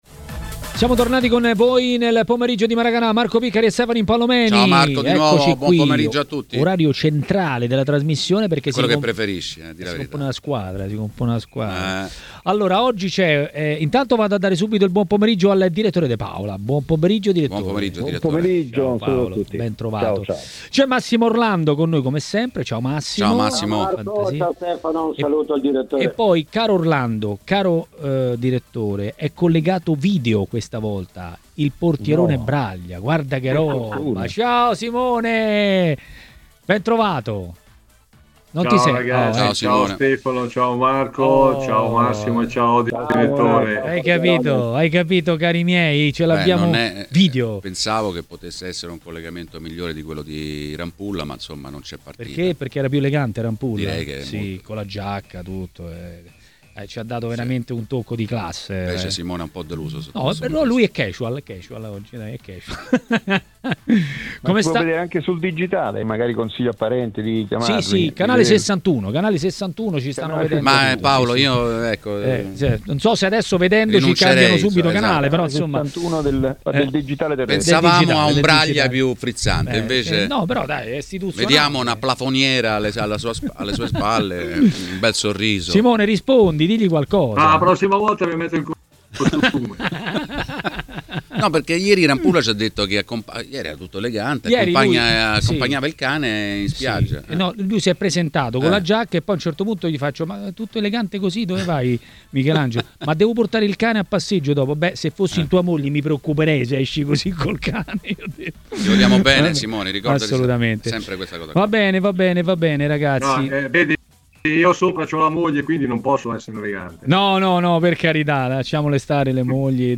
L'ex calciatore Massimo Orlando ha parlato dei temi del giorno a Maracanà, trasmissione di TMW Radio.